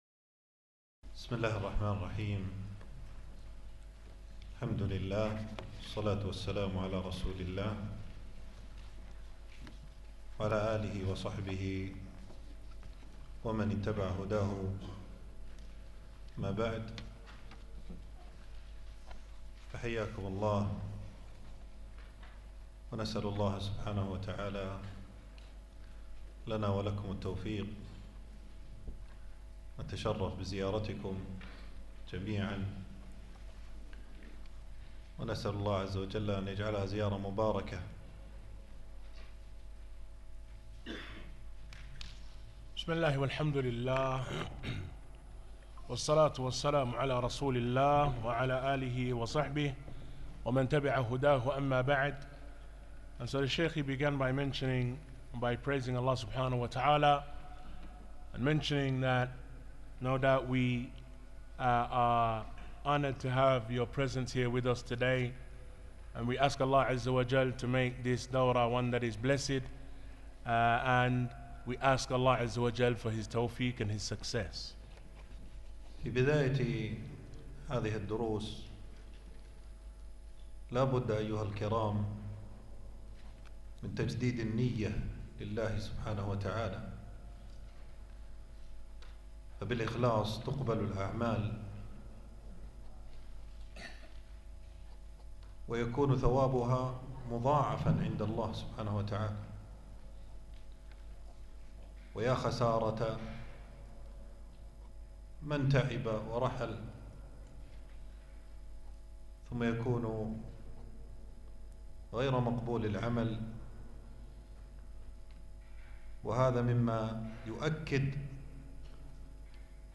المكان: درس ألقاه يوم الجمعة 8 جمادى الأول 1446هـ في مسجد السعيدي.